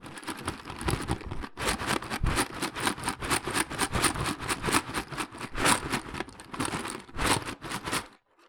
• pasta box shaken.wav
Experience the unique, rhythmic sound of a pasta box being shaken. This sound effect captures the dry rustle and clatter of pasta pieces moving against each other and the box.
pasta_box_shaken_At4.wav